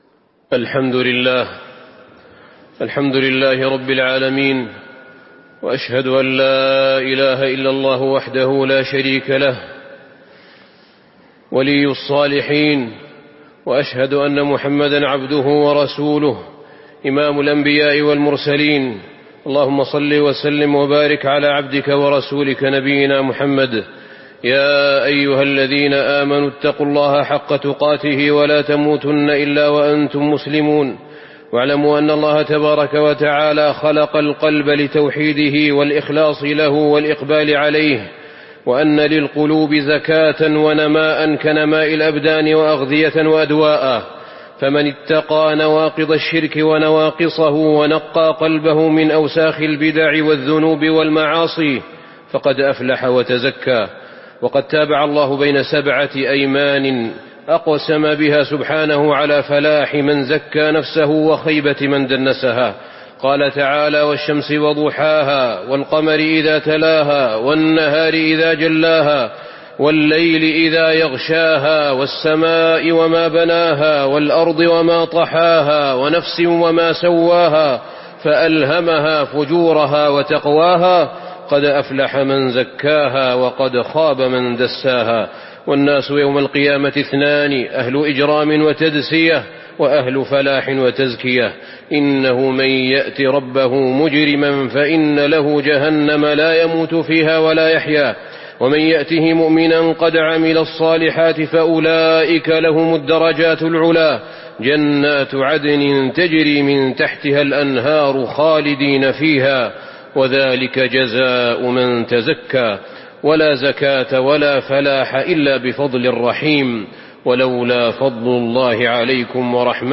تاريخ النشر ١٥ ربيع الثاني ١٤٤٦ هـ المكان: المسجد النبوي الشيخ: فضيلة الشيخ أحمد بن طالب بن حميد فضيلة الشيخ أحمد بن طالب بن حميد صلاح القلوب